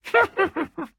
mob / witch / ambient4.ogg